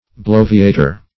A23) -- blo"vi*a*tor, n. -- blo"vi*a*tion, n.